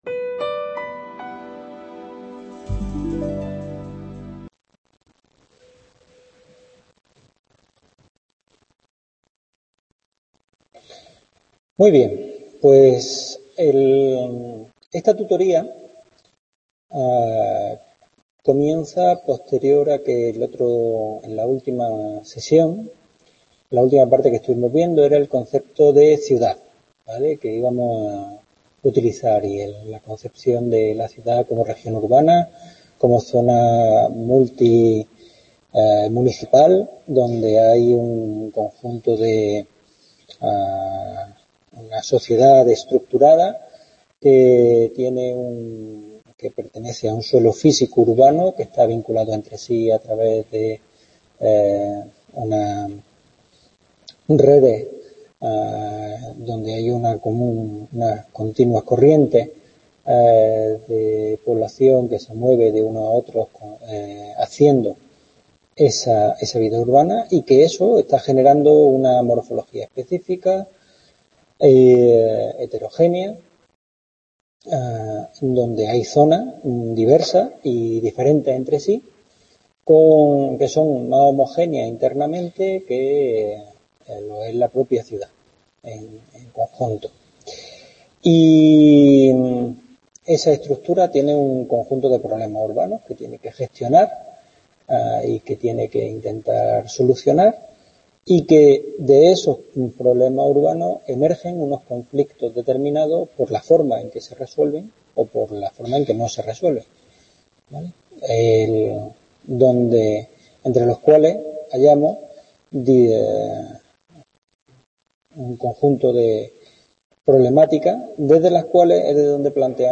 Tutoría 3. Primera Parte | Repositorio Digital